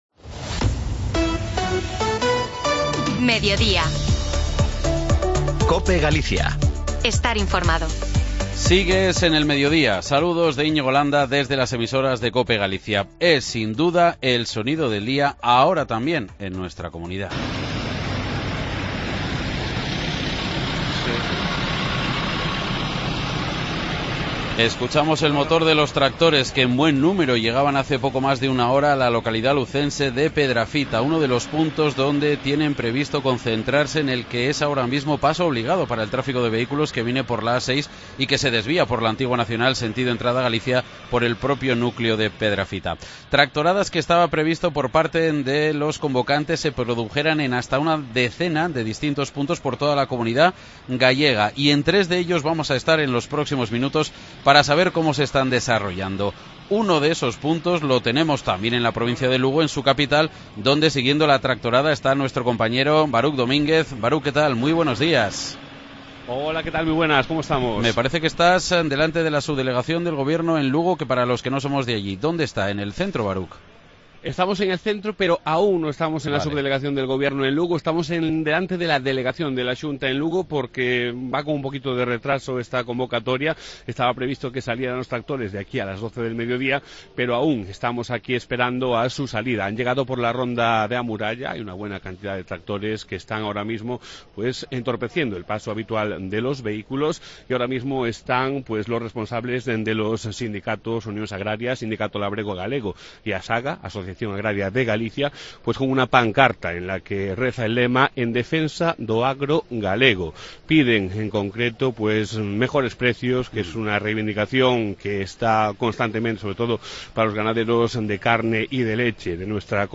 AUDIO: Estamos en directo en tres de las tractoradas que se registran en la comunidad de Galicia este martes escuchando las reclamaciones y quejas...